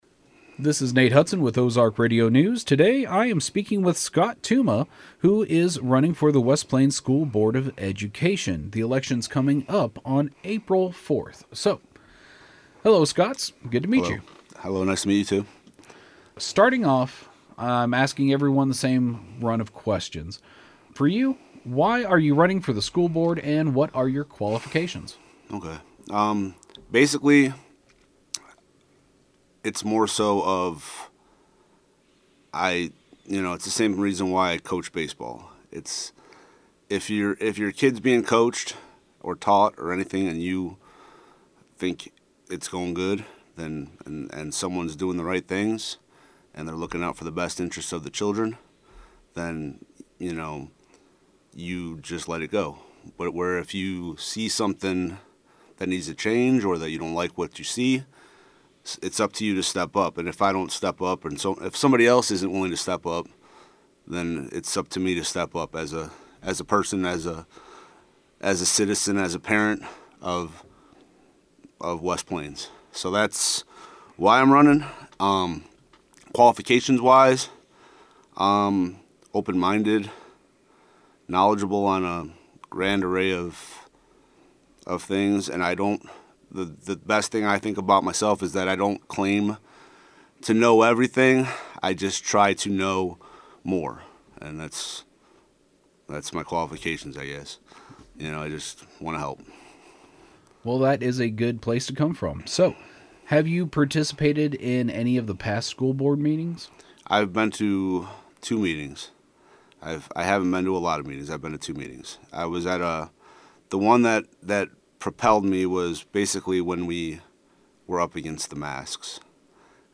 News Team 7 will be releasing our interviews with each of the candidates throughout the week.